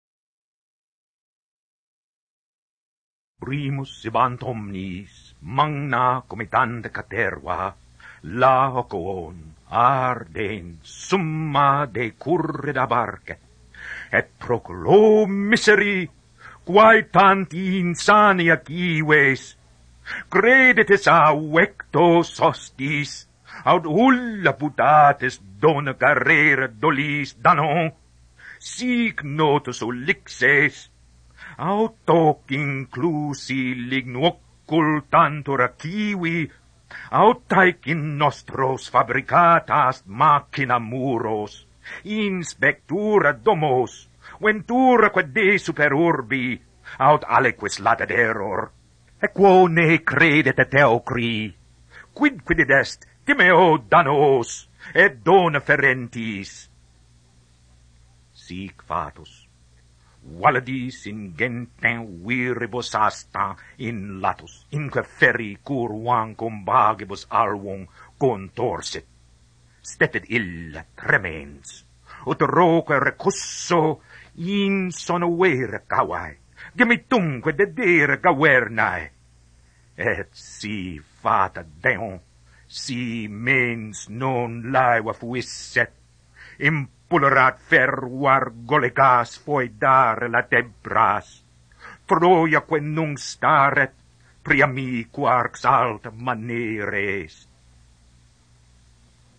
• Tracks broken into individual poems, odes, and digestible portions of longer works.
Latin Aloud list of audio recordings on the CD.